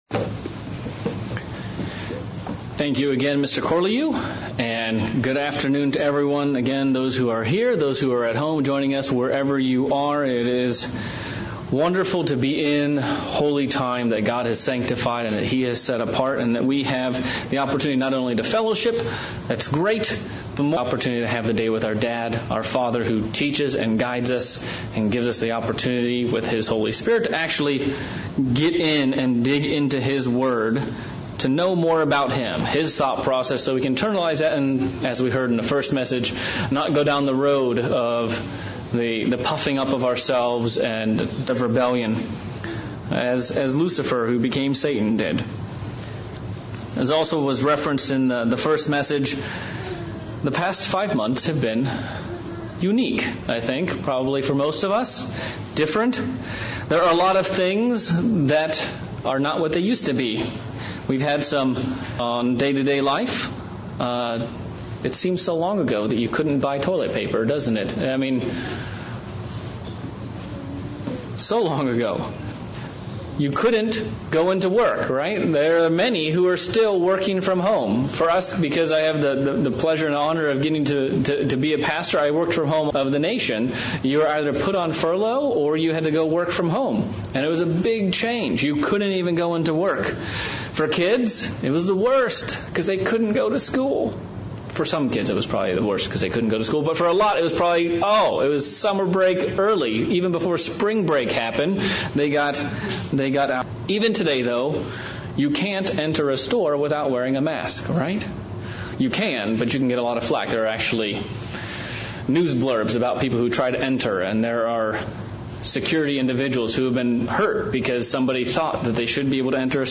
Sermon looking at the attitudes of God's people thru the pages of the Bible and the attitude of "I Can't" vs. "I Won't".